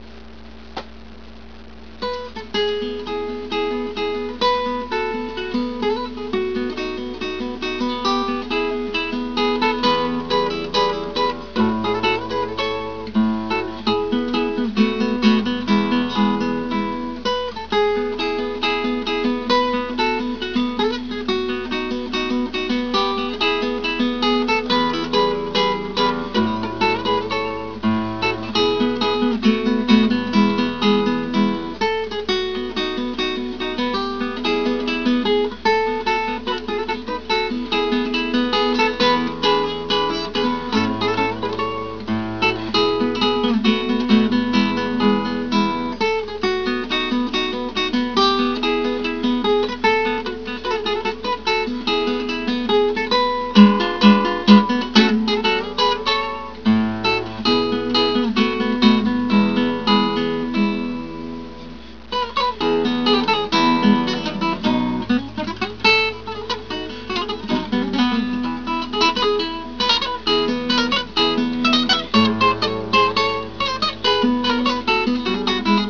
guiter1.wav